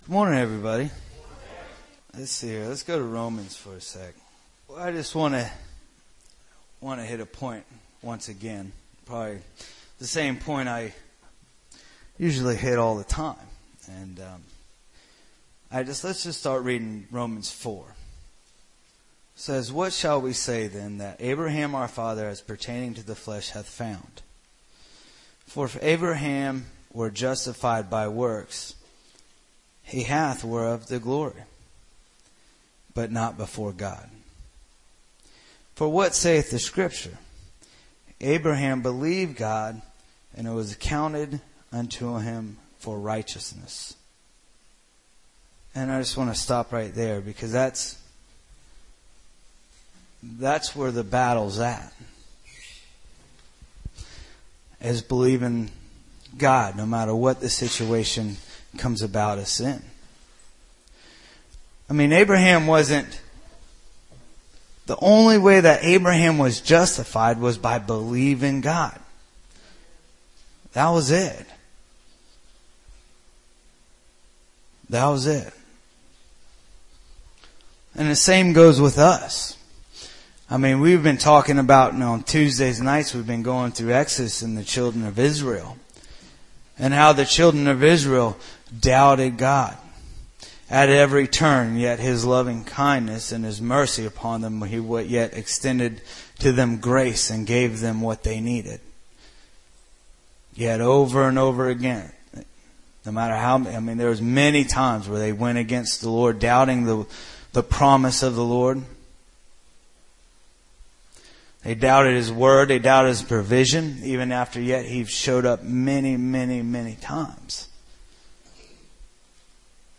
Sunday South Carolina Church Service 11/17/2013 | The Fishermen Ministry